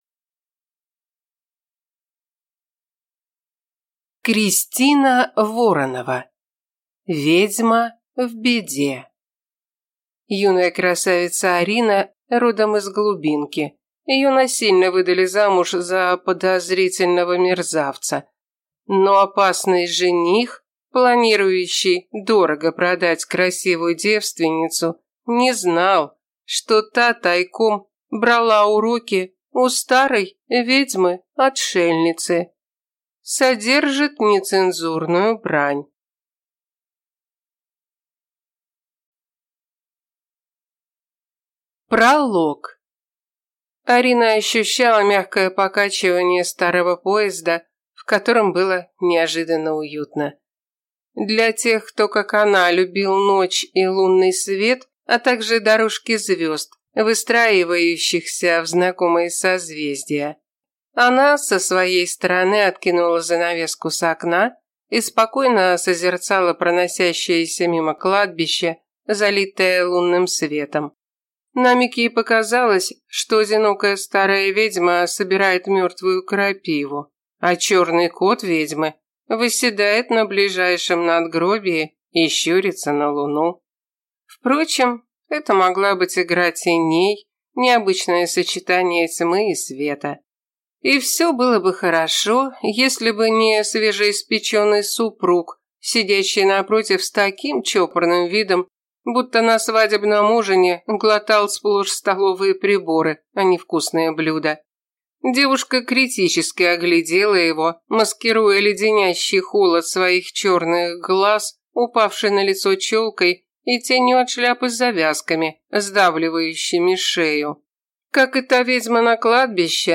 Аудиокнига Ведьма в беде | Библиотека аудиокниг